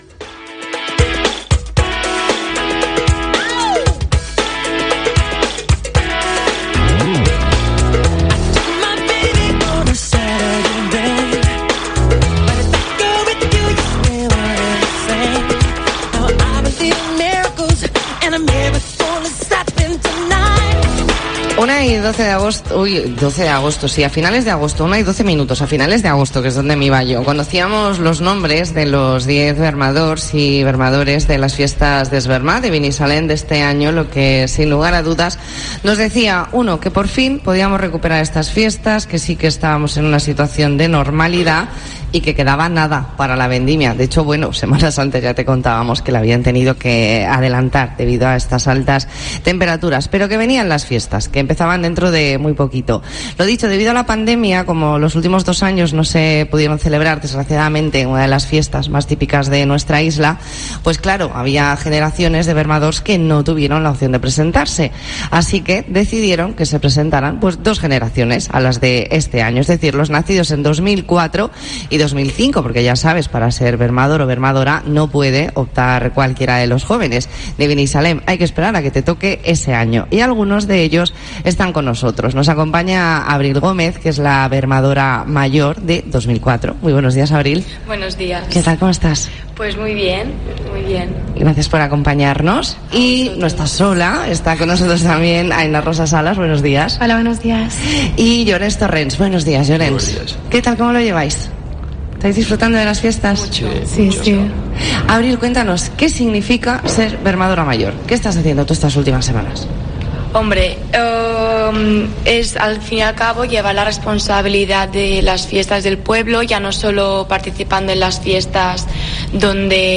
Entrevista en La Mañana en COPE Más Mallorca, jueves 22 de septiembre de 2022.